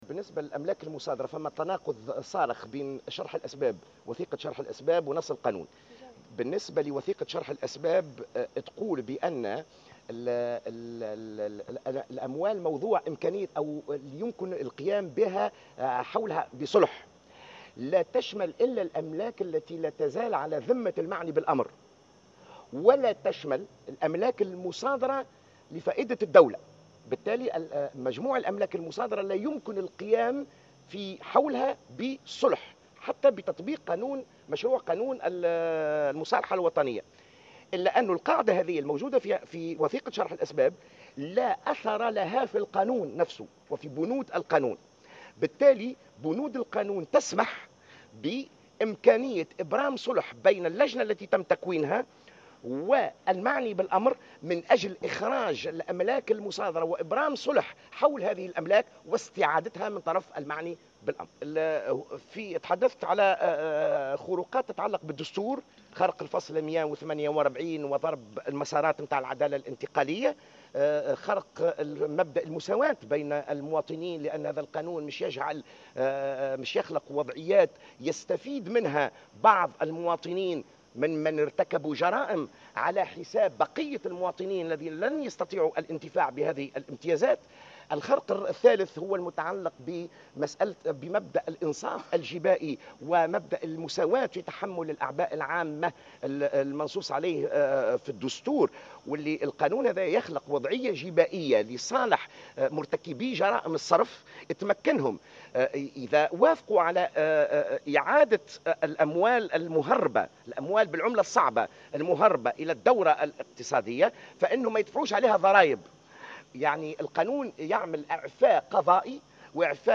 دعا المنسق العام لشبكة "دستورنا"، جوهر بن مبارك خلال ندوة صحفية عقدتها هيئة الحقيقة والكرامة اليوم الجمعة إلى عرض قانون المصالحة على الاستفتاء الشعبي.